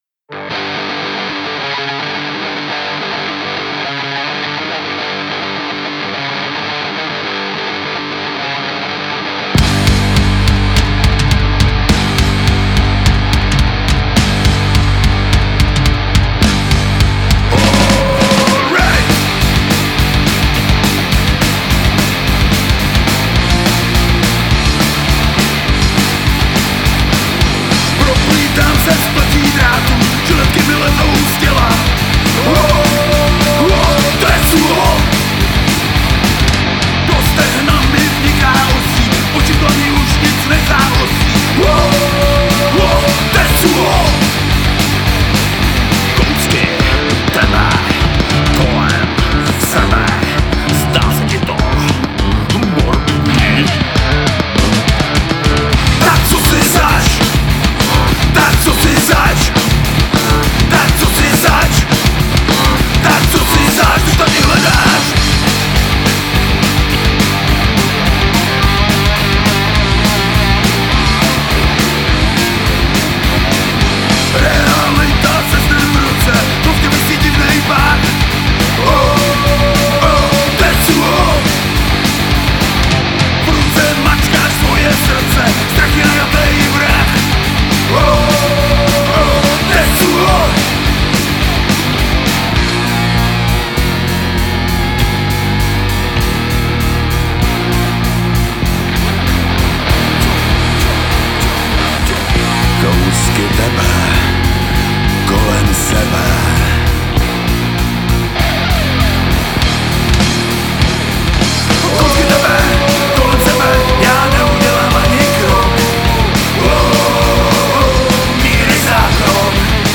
...jojo, na slap je danej kompresor/limiter. smile
....já tak trochu nevím. roll  První verze je basovější, druhá ostřejší. smile
Je to obojí takovej zkušebnovej zvuk, všecky stopy pěkně na hromádce.